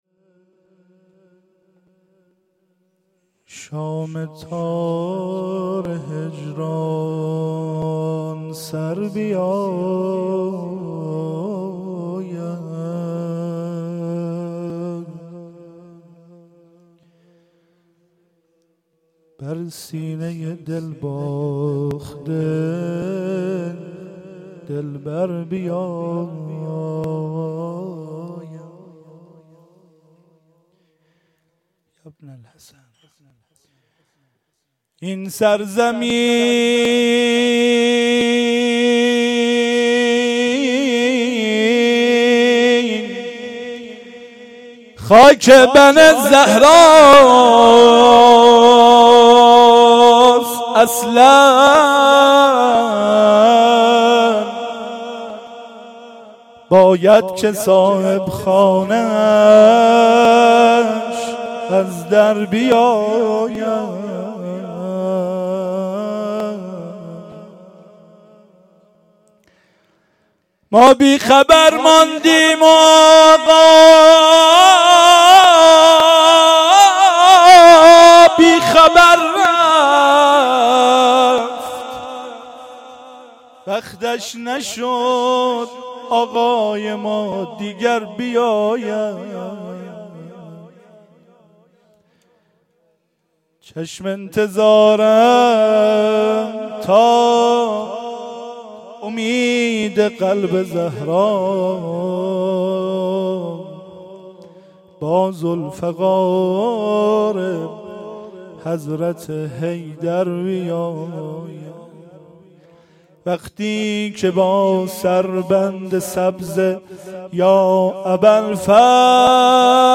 شهادت-امام-جواد-ع-97-روضه-آخر.mp3